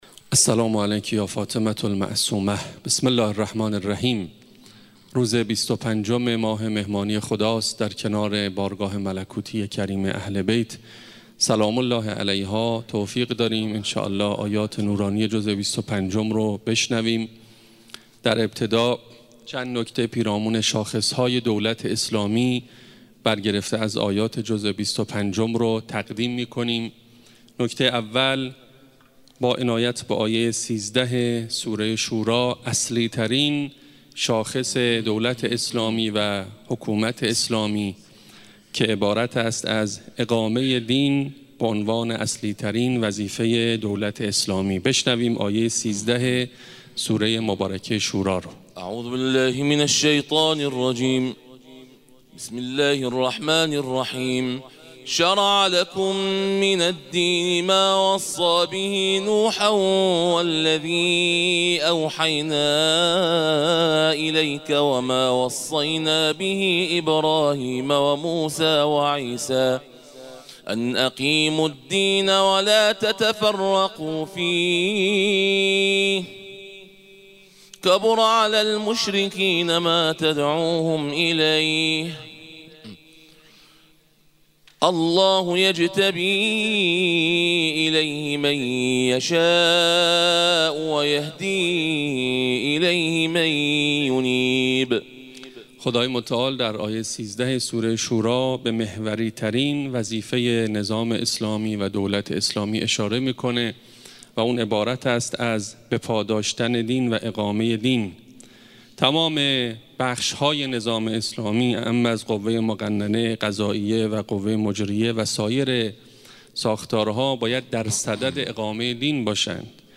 شاخصه های دولت اسلامی - حرم حضرت فاطمه معصومه (س) با ترافیک رایگان